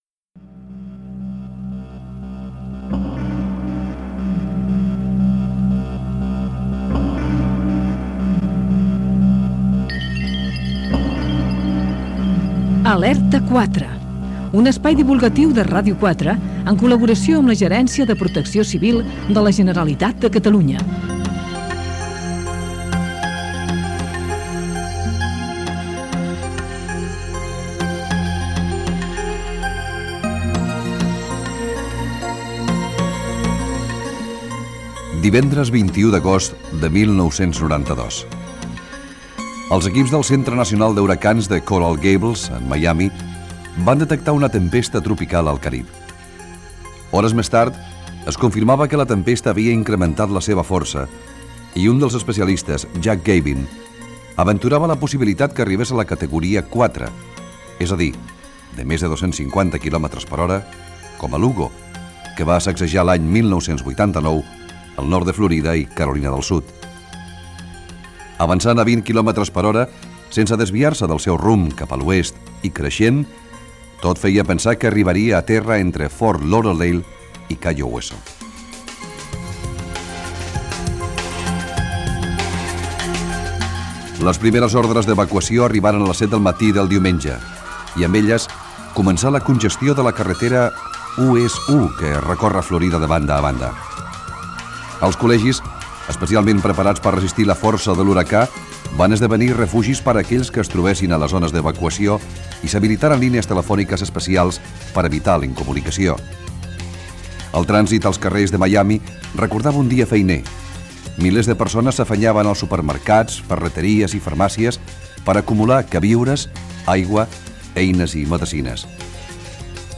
entrevista al meteoròleg Tomàs Molina sobre el comportament dels fenòmens atmosfèrics
Gènere radiofònic Entreteniment